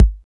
Index of /90_sSampleCDs/Roland - Rhythm Section/DRM_Drum Machine/KIK_Cheese Kicks
KIK SHARP 08.wav